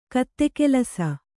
♪ katte kelasa